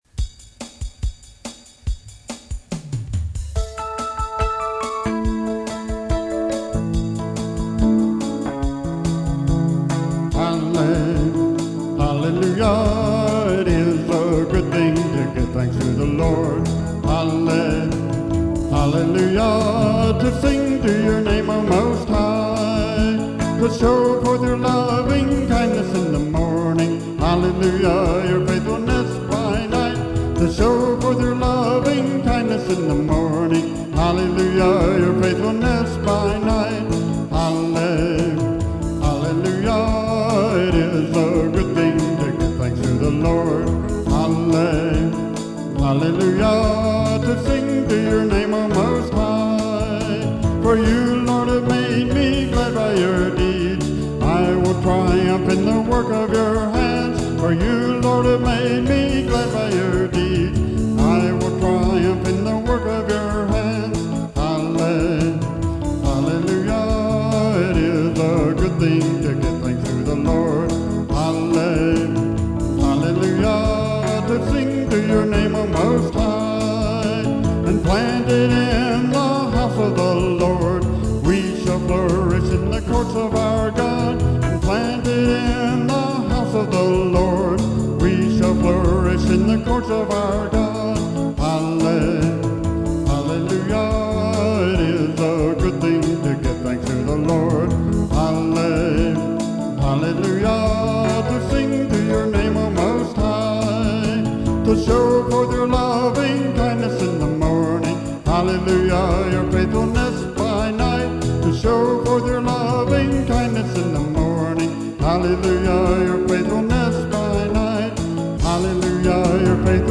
Download the MP3 (2:12, 1.3MB) (Home Studio?)